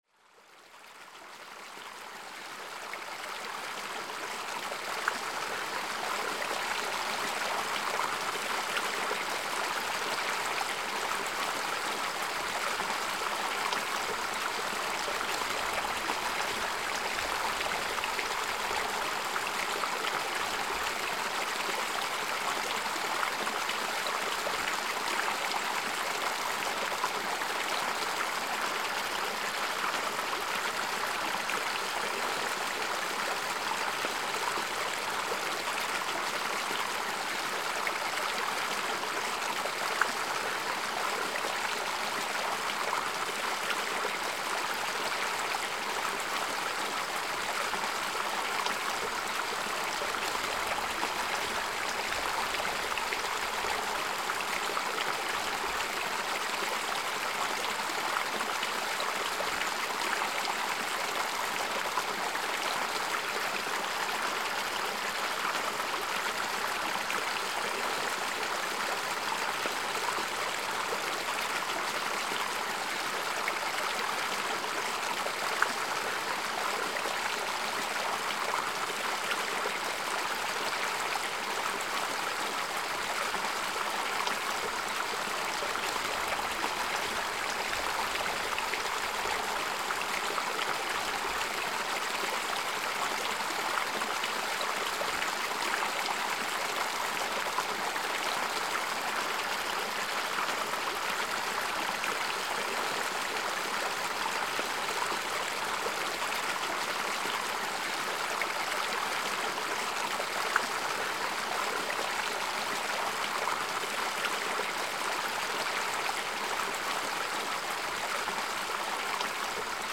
栃木県佐野市の湧き水です。